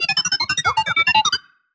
sci-fi_driod_robot_emote_beeps_05.wav